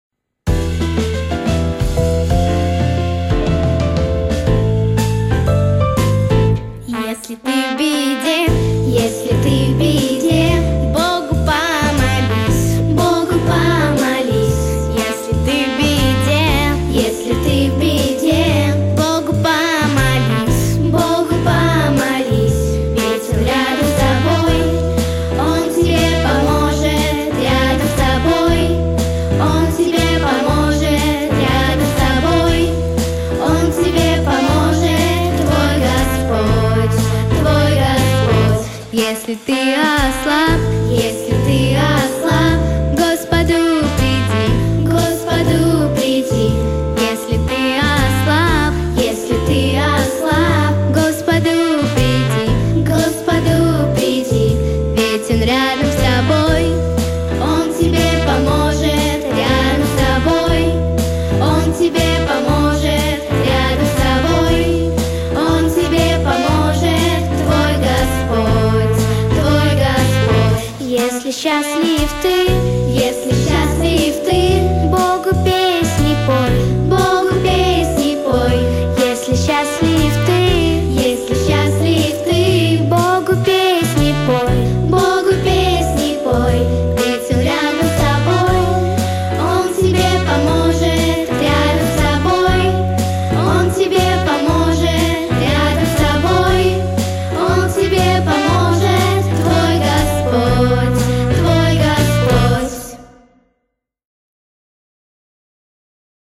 Христианские песни для малышей и песни для школьников.